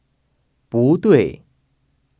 [búduì] 부뚜이